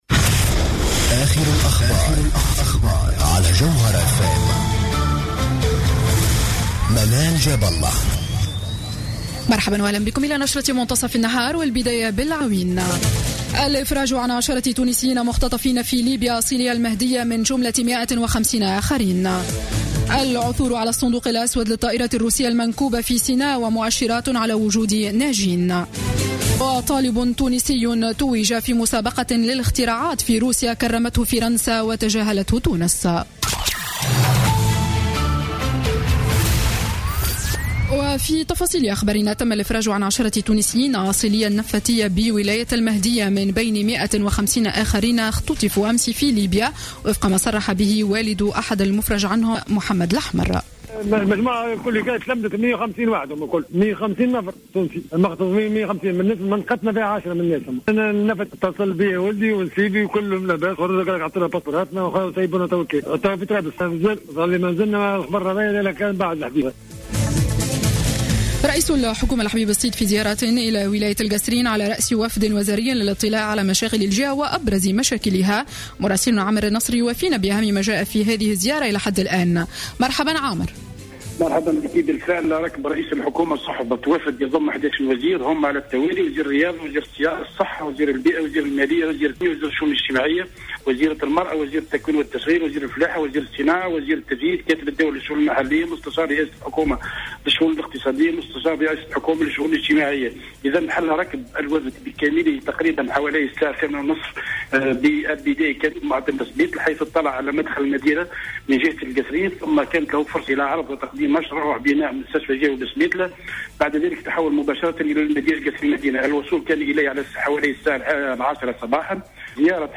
نشرة أخبار منتصف النهار ليوم السبت 31 أكتوبر 2015